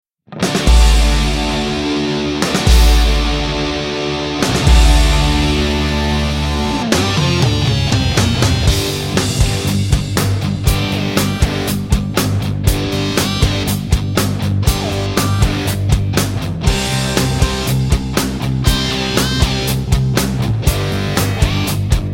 Listen to the instrumental version of this song.